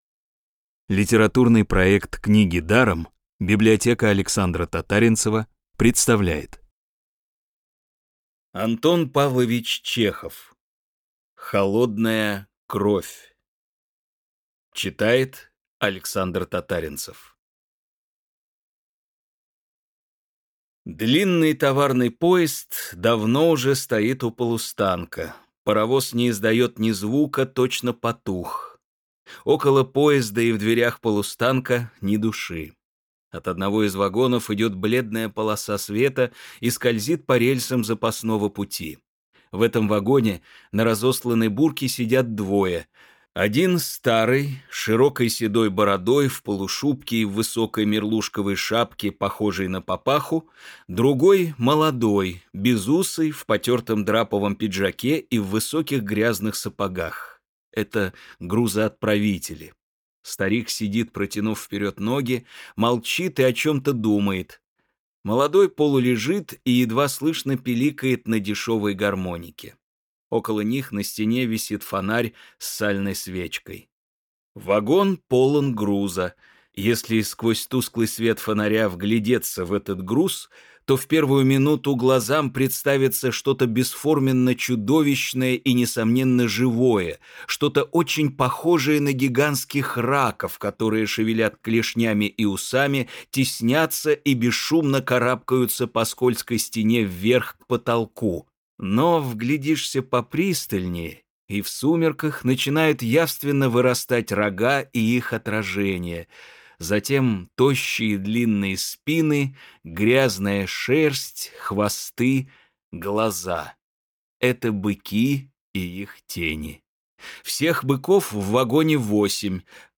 Бесплатная аудиокнига «Холодная кровь» от Рексквер.
Классическую литературу в озвучке «Рексквер» легко слушать и понимать благодаря профессиональной актерской игре и качественному звуку.